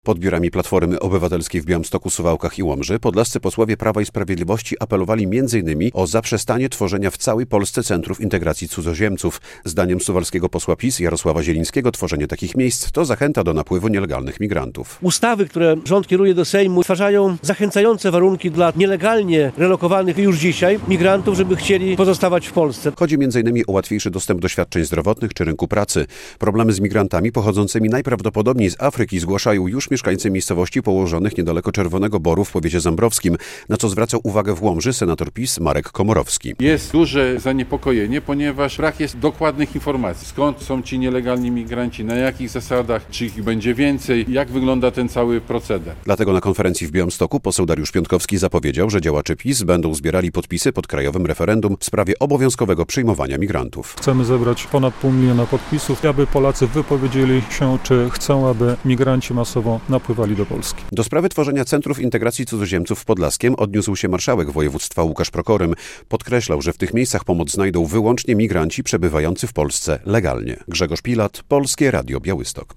Działacze Prawa i Sprawiedliwości zapowiadają zbiórkę podpisów pod wnioskiem o krajowe referendum ws. obowiązkowego przyjmowania imigrantów. Mówili o tym podczas konferencji prasowych w Białymstoku, Łomży i Suwałkach podlascy parlamentarzyści PiS.